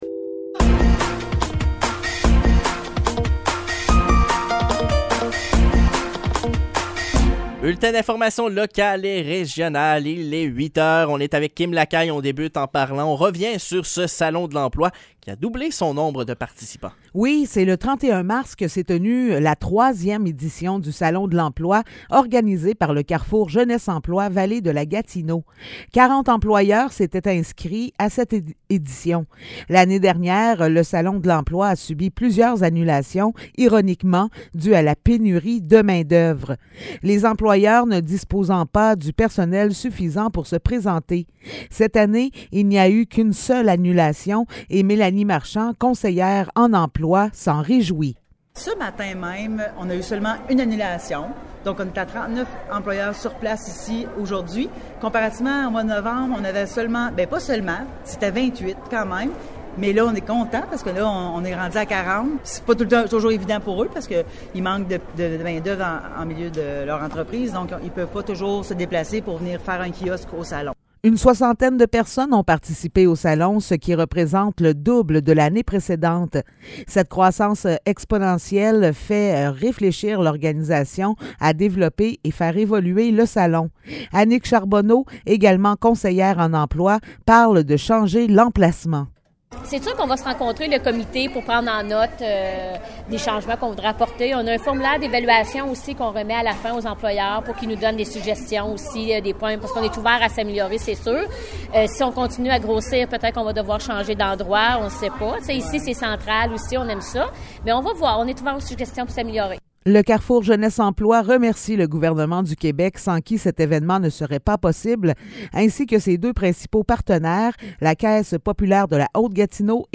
Nouvelles locales - 3 avril 2023 - 8 h